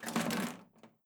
gps_to_glove_compartment.wav